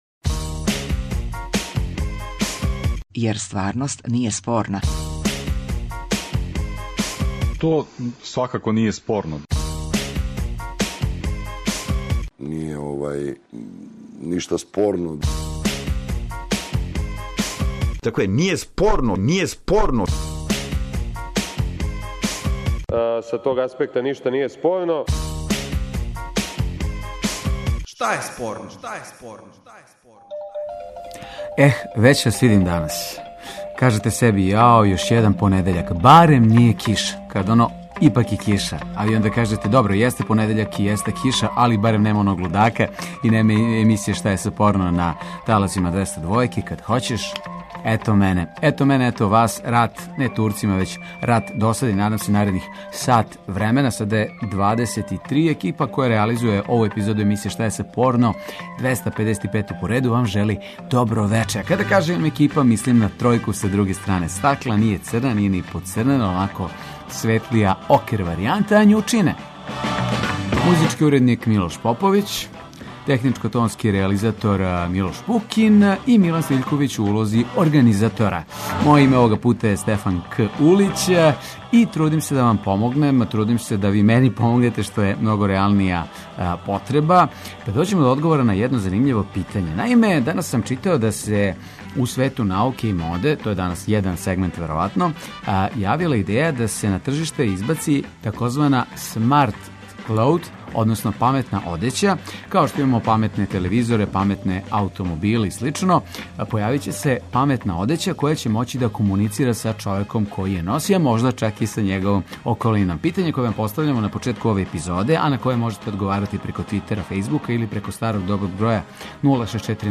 Радијски актуелно - забавни кабаре интерактивног карактера.